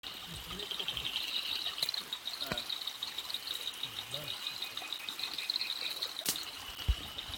Utham'S Bush Frog Scientific Name: Raorchestes Uthamani